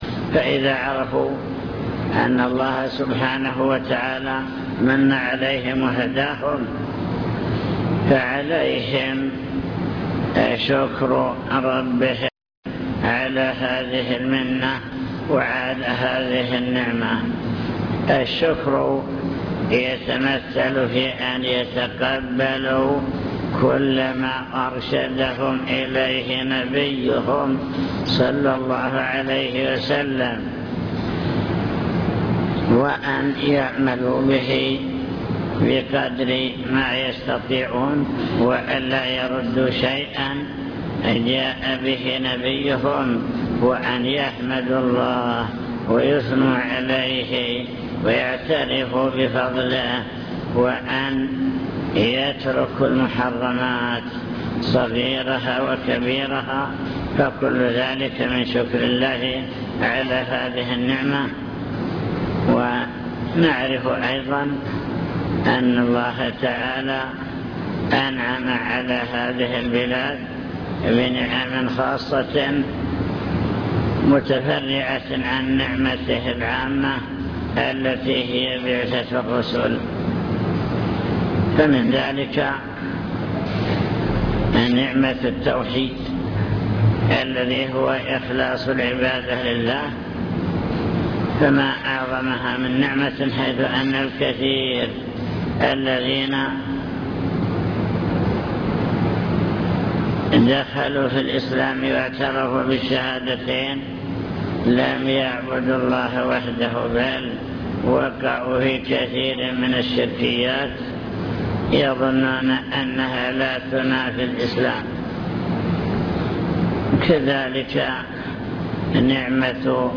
المكتبة الصوتية  تسجيلات - لقاءات  كلمة في مسجد نعم الله لا تحصى